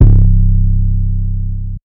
Spinz v2 [808].wav